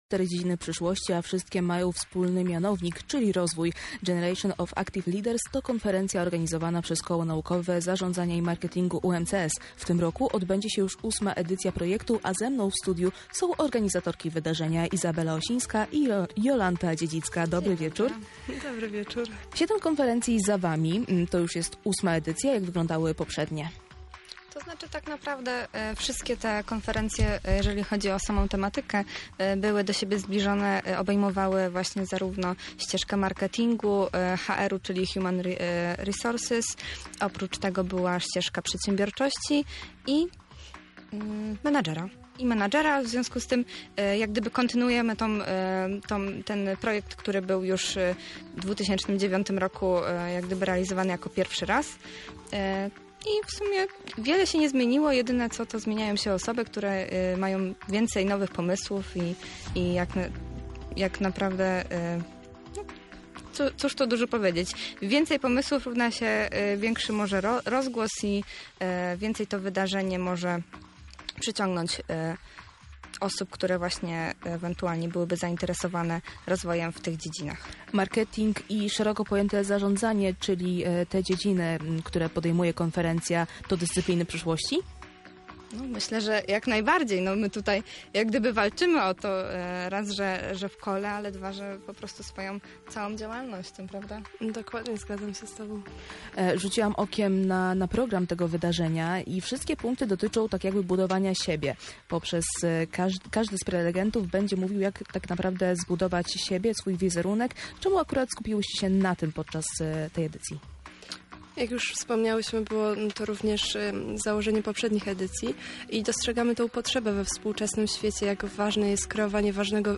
Konferencja GOAL – rozmowa w Centrum Uwagi